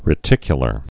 (rĭ-tĭkyə-lər)